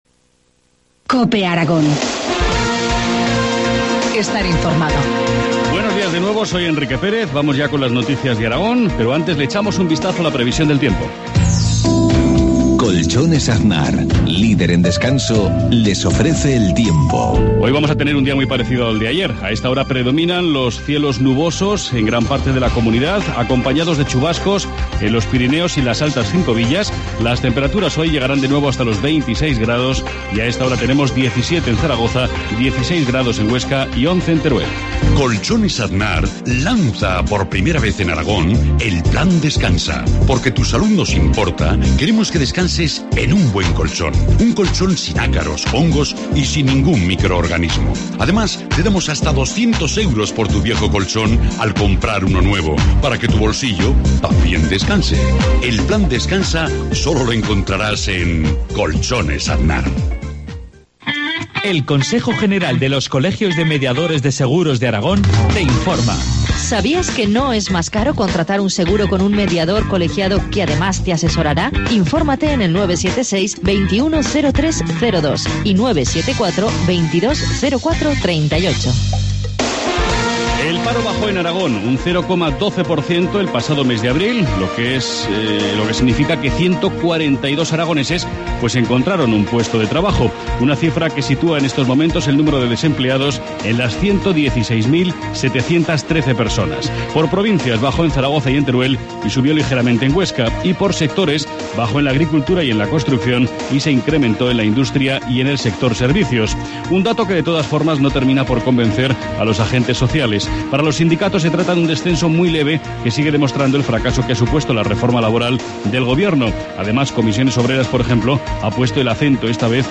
Informativo matinal, martes 7 de mayo, 7.53 horas